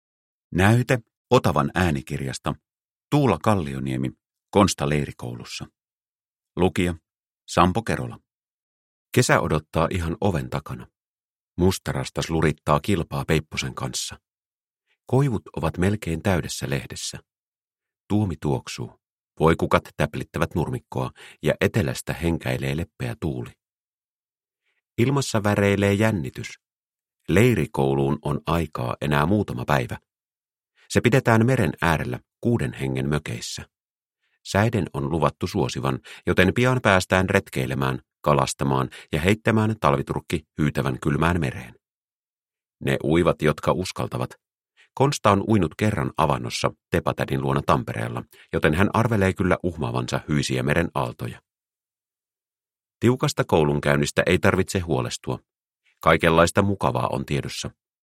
Konsta leirikoulussa – Ljudbok